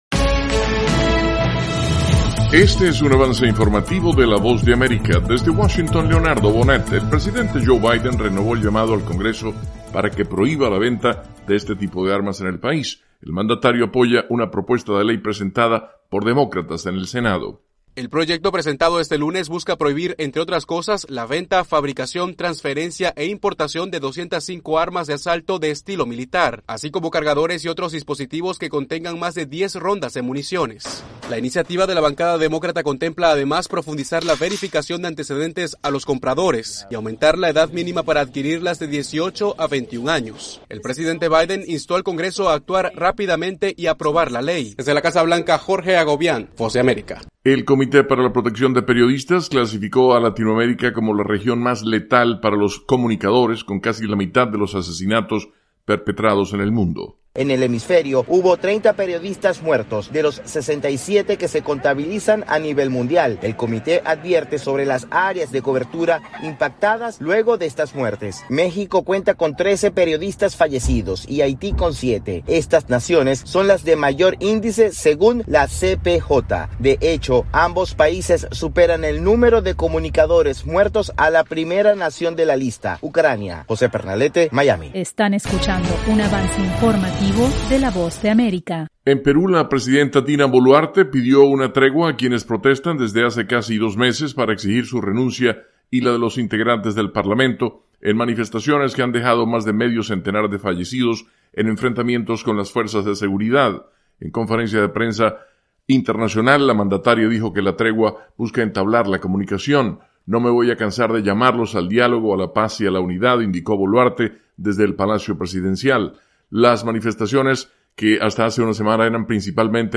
Avance Informativo 7:00 PM
El siguiente es un avance informativo presentado por la Voz de América, desde Washington